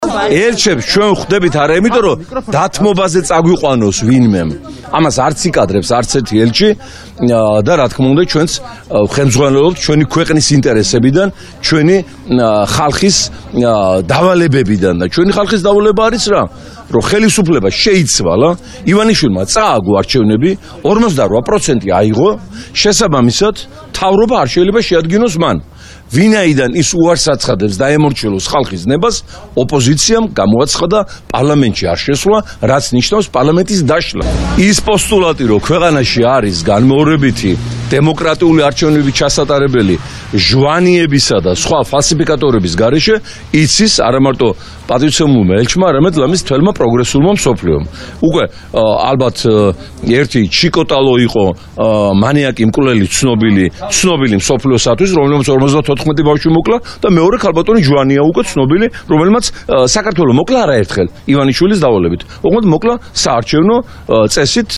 მოვისმინოთ შალვა ნატელაშვილის ხმა